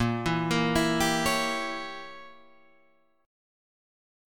A#dim7 chord